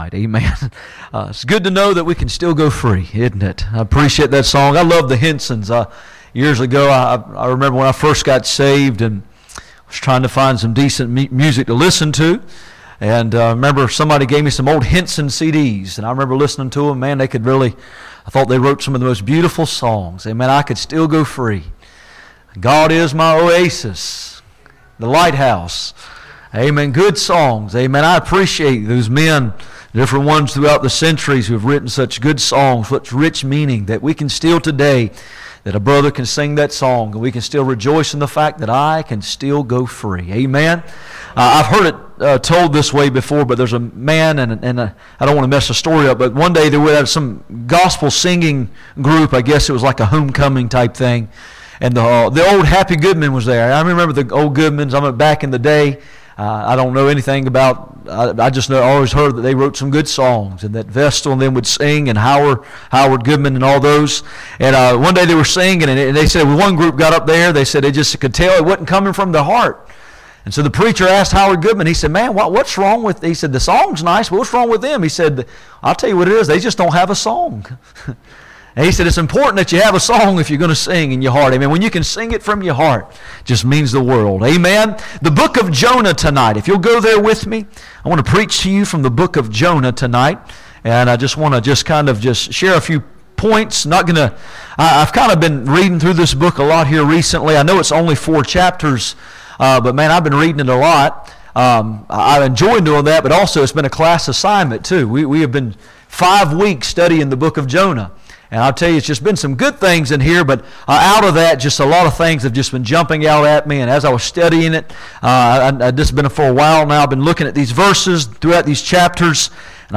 None Passage: Jonah 1:1-4 Service Type: Sunday Evening %todo_render% « What it means to love the Lord What is Sunday?